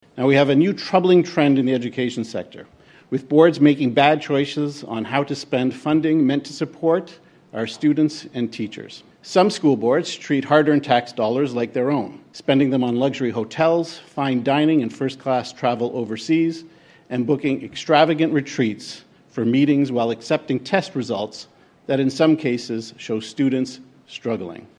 Education Minister Paul Calandra outlined several concerns the province has had recently regarding how school boards are managing their finances during a press conference.